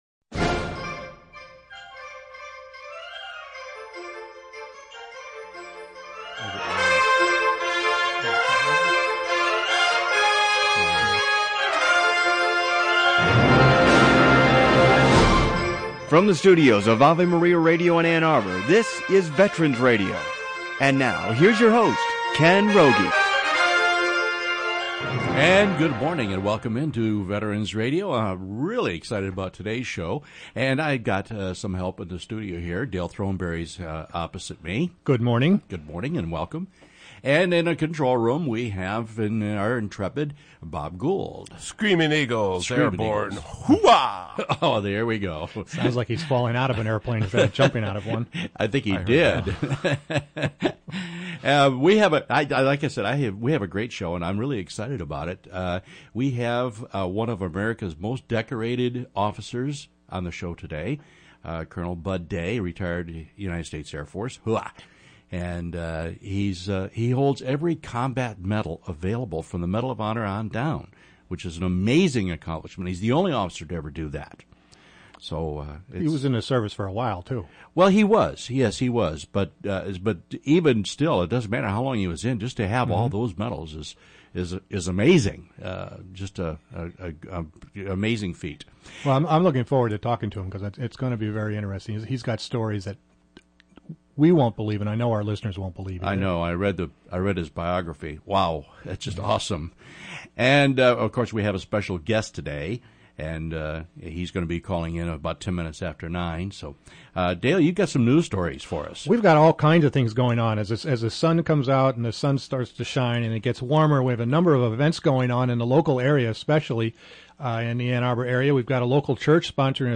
This week on Veterans Radio, we talk to Medal of Honor recipient Colonel Bud Day.